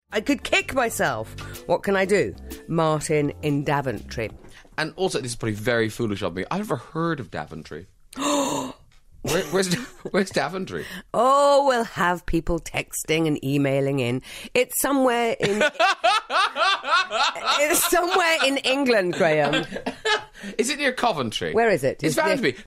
BBC Radio Presenter Graham Norton admitting he's never heard of Daventry